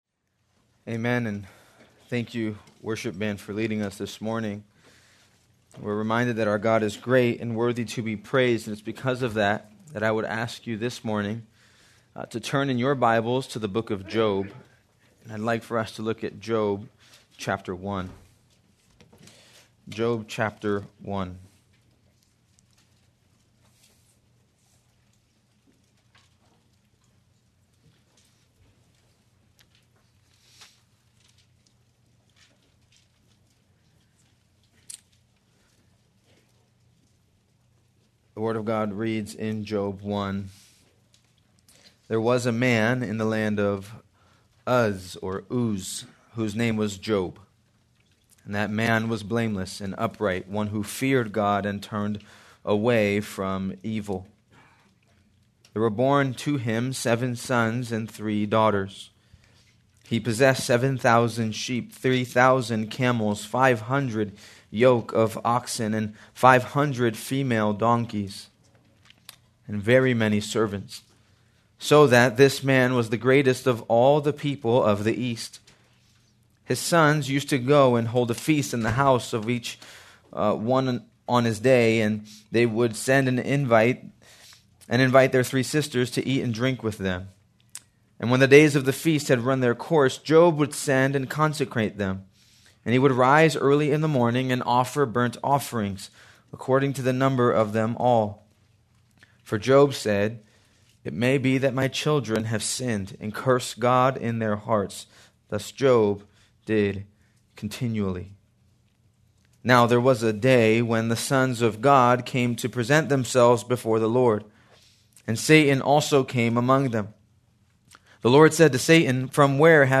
January 12, 2025 - Sermon